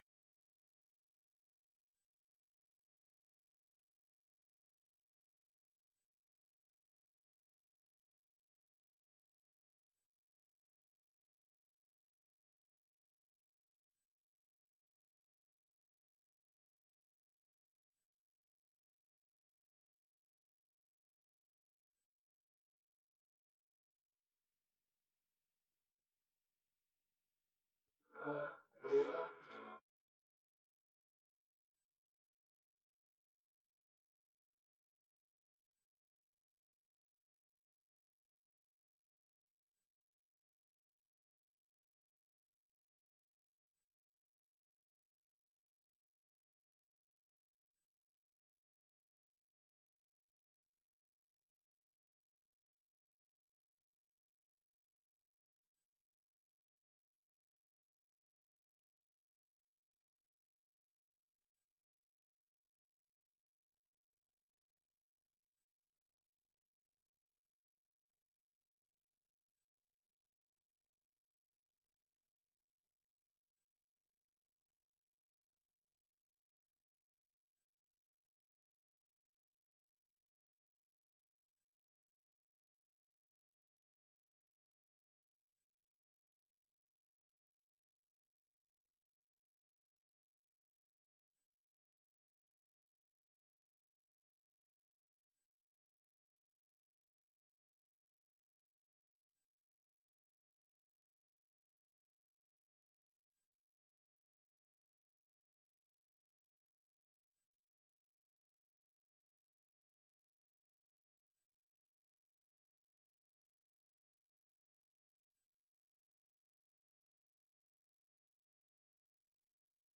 Backing Vocals